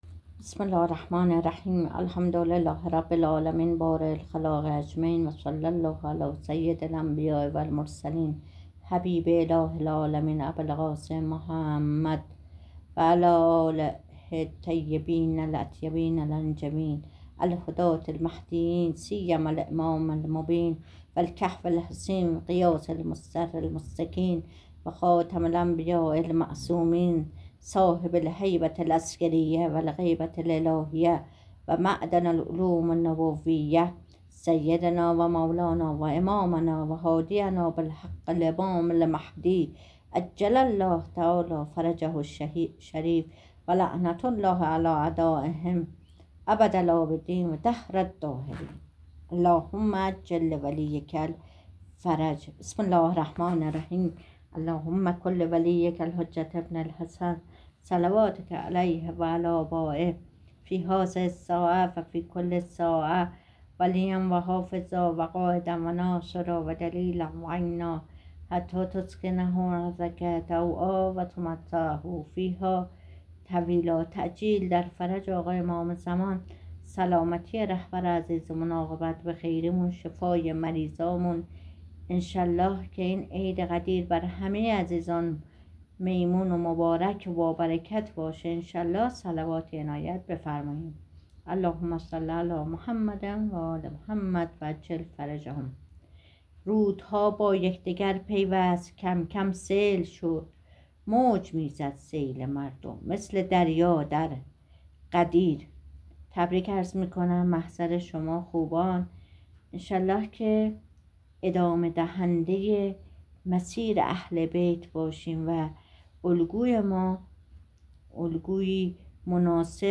در گفت‌وگو با خبرنگار خبرگزاری حوزه در اراک